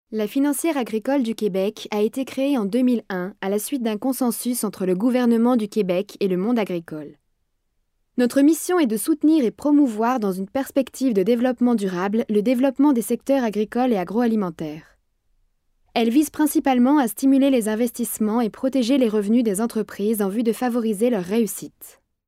Extrait d'un Documentaire de "La financière agricole du Québec"